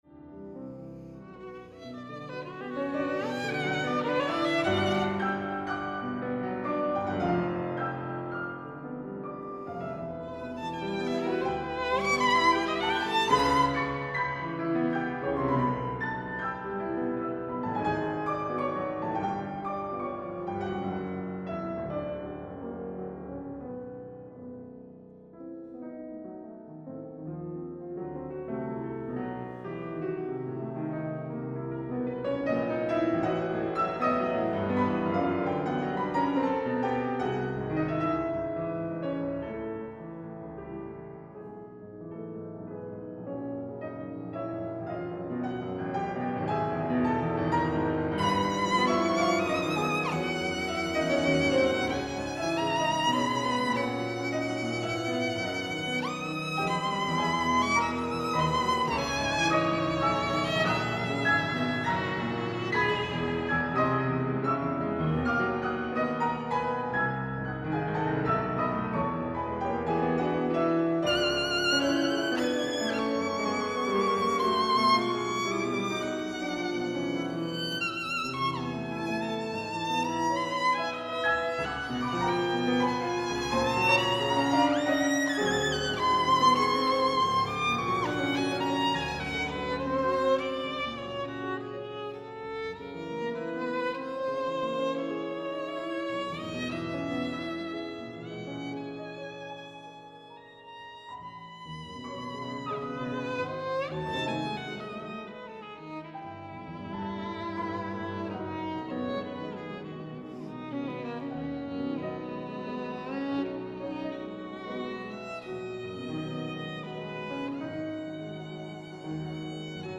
Sonata for violin and piano